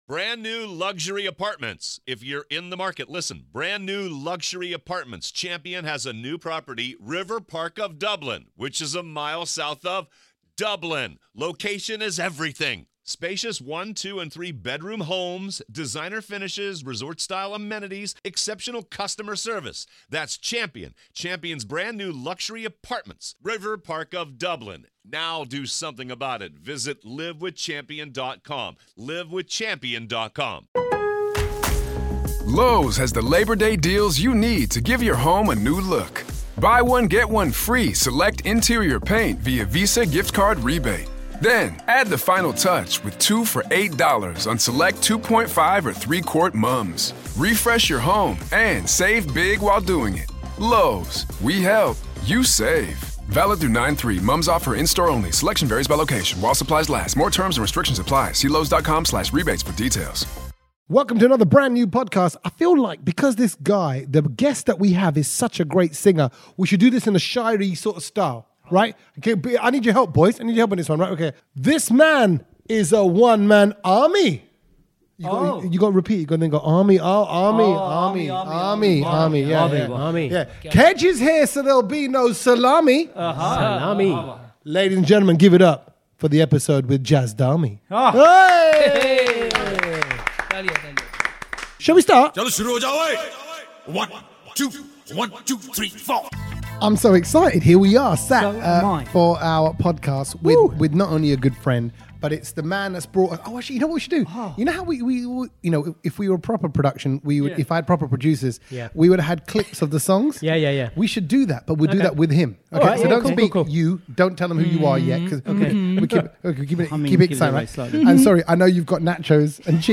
The boys finally got Jaz around the kitchen table to talk food, music and have a go at Podioke